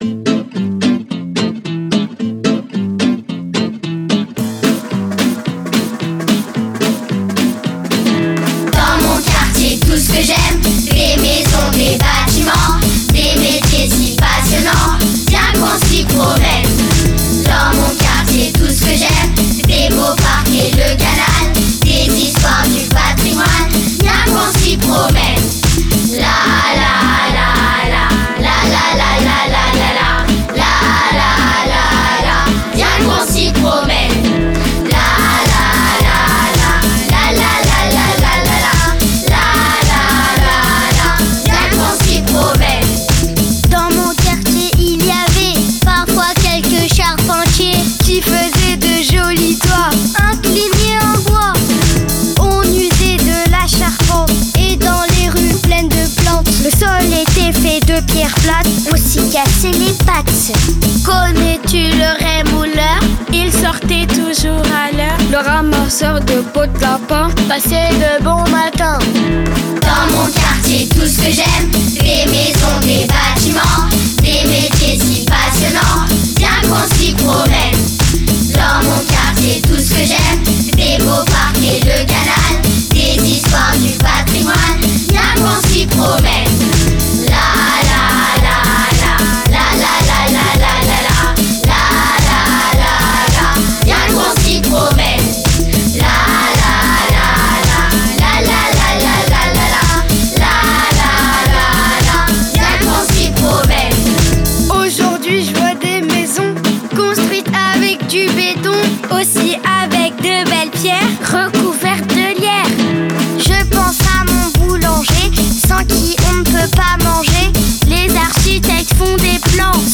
La chanson ainsi conçue a été enregistrée à L’Autre Canal, mêlant les voix des enfants et des résidents dans une interprétation à la fois chantée et parlée.